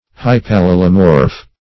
Hypallelomorph \Hyp`al*le"lo*morph\, n.